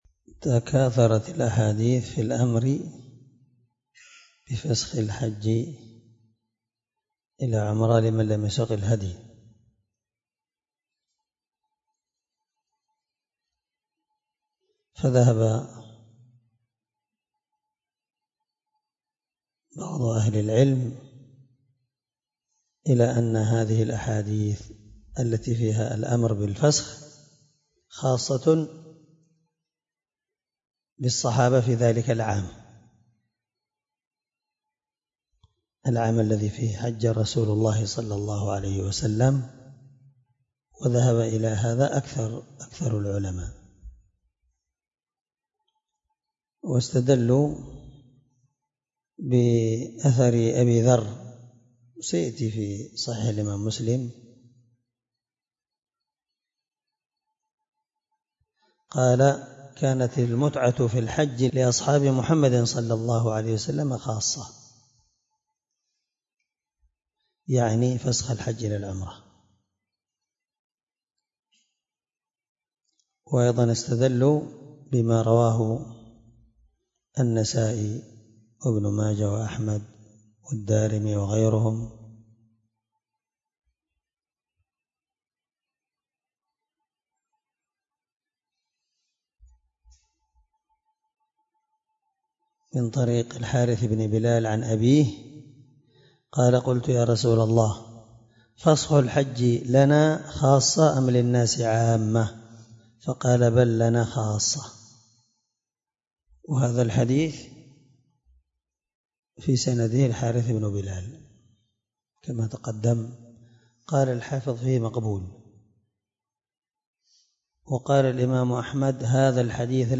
732الدرس 17من شرح كتاب الحج حديث رقم(1217-1216) من صحيح مسلم
دار الحديث- المَحاوِلة- الصبيحة.